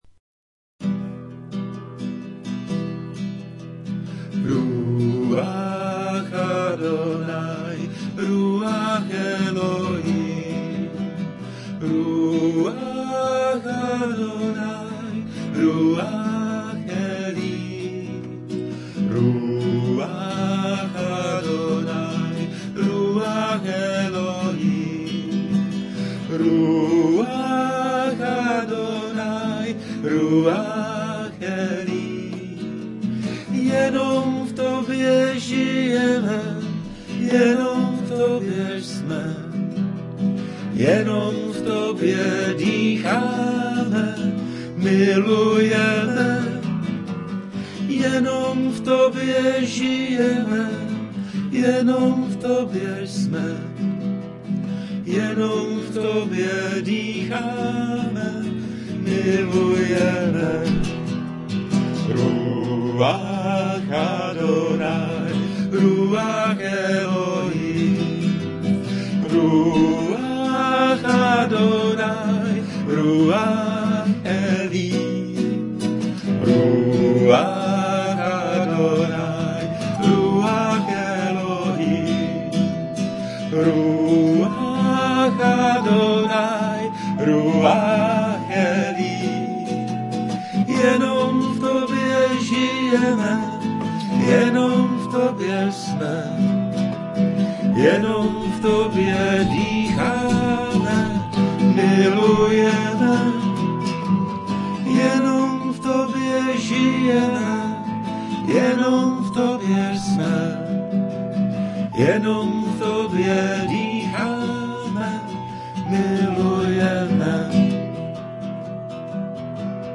A  je tu funglovka, festivalovka, ke chvalám.
Demo